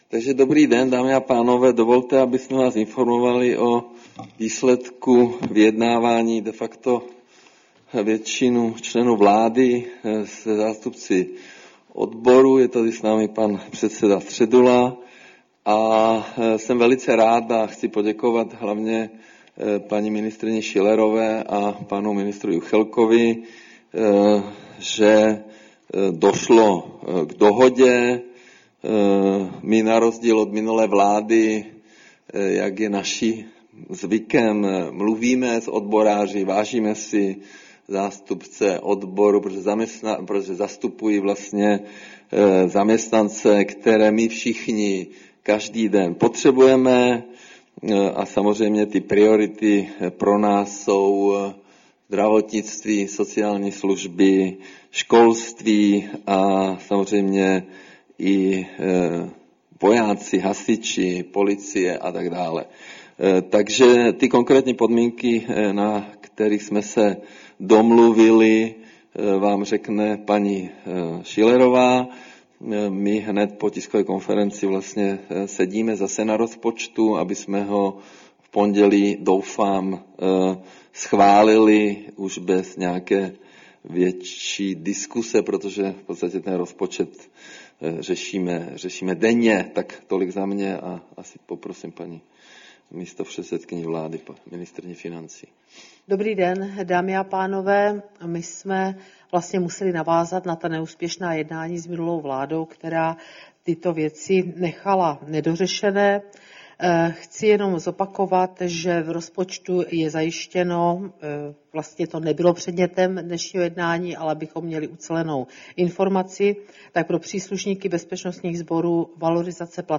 Tisková konference po jednání o valorizaci platových tarifů, 22. ledna 2026